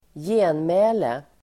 Ladda ner uttalet
genmäle substantiv, reply Uttal: [²j'e:nmä:le] Böjningar: genmälet, genmälen, genmälena Synonymer: replik, respons, svar, svara Definition: svar i (skriftlig eller muntlig) debatt (response in a (written or oral) debate)